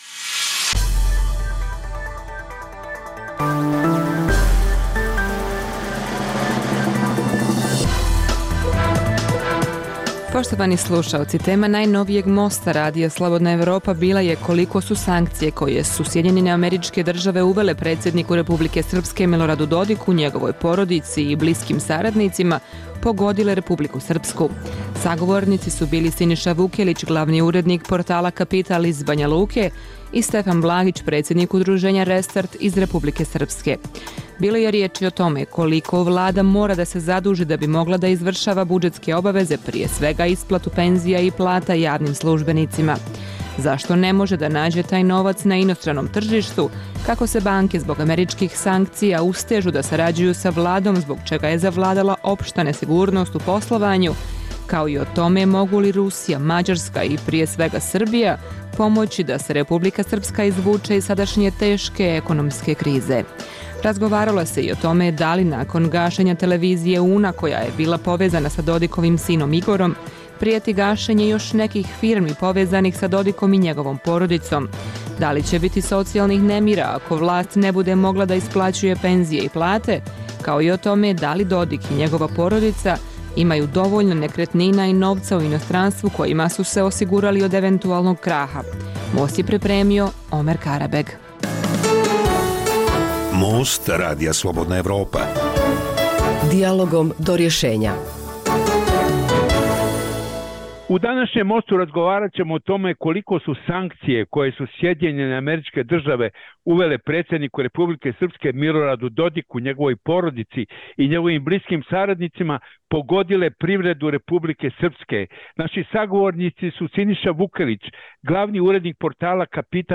Emisija namijenjena slušaocima u Crnoj Gori. Sadrži lokalne, regionalne i vijesti iz svijeta, rezime sedmice, tematske priloge o aktuelnim dešavanjima u Crnoj Gori i temu iz regiona.